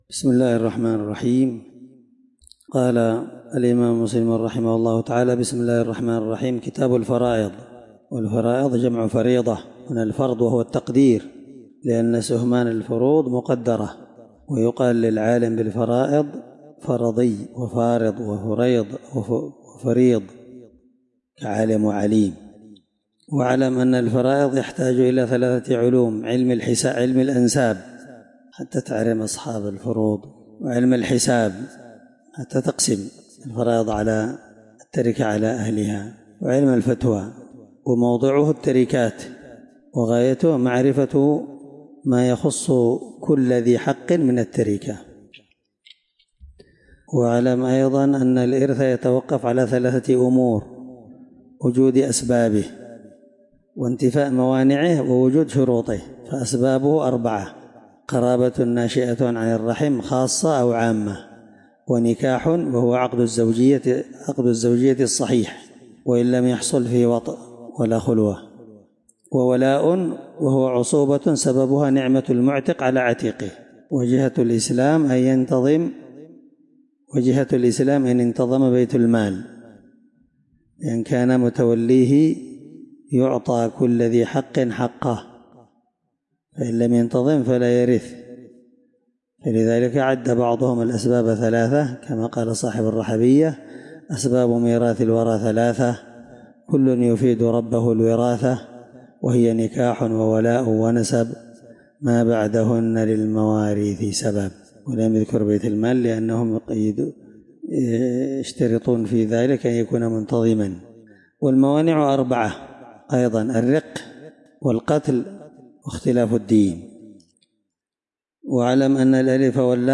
الدرس1من شرح كتاب الفرائض حديث رقم(1614) من صحيح مسلم